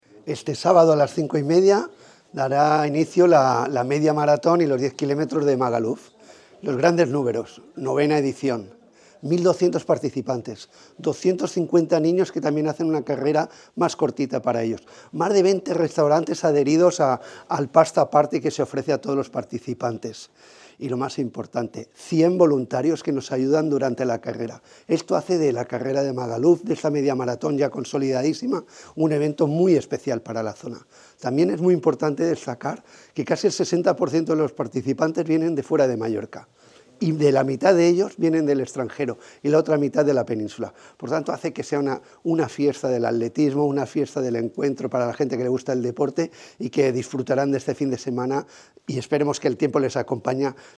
mayors-statements-half-marathon.mp3